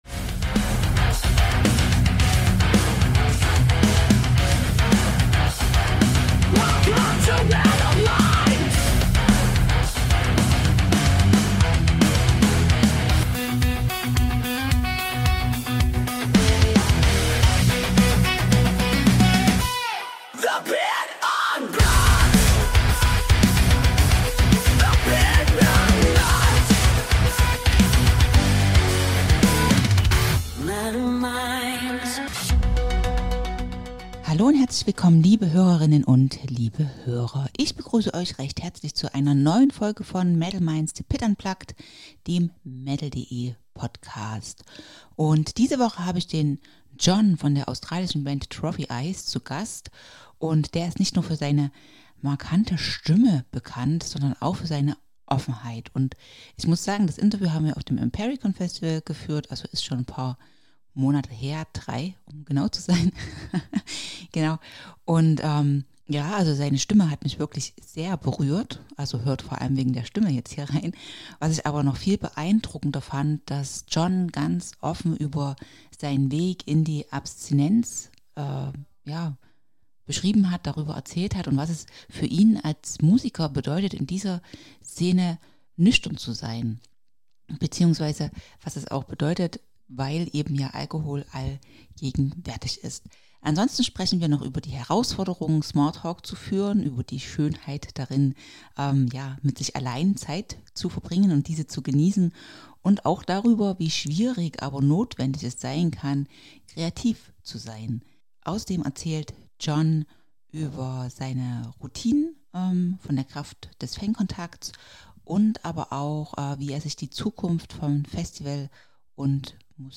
Ein Gespräch über Musik, Menschlichkeit und darüber, wie wichtig echte Verbindung ist – sowohl auf als auch neben der Bühne.